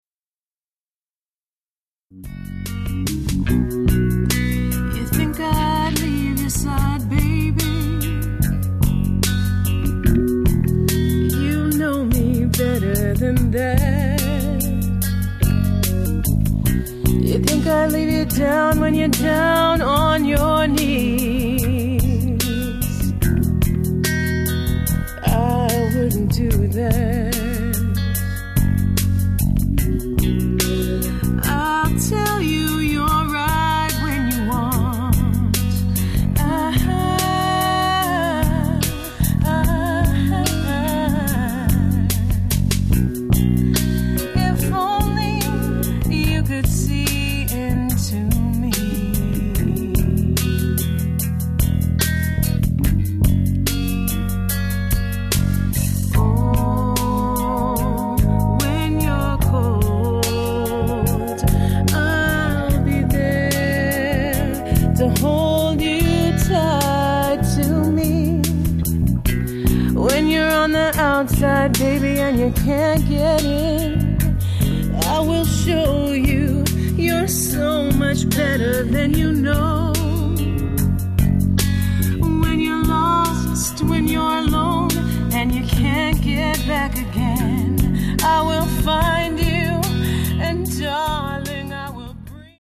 This band is Hawaii's most visible and versatile band.